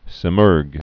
(sĭ-mûrg)